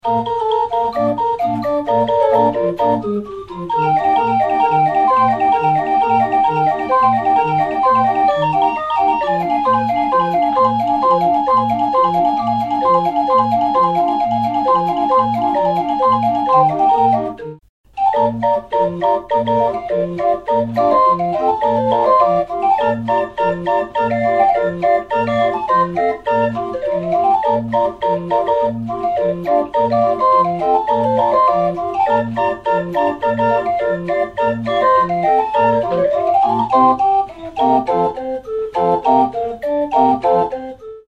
1. 31er D-Orgel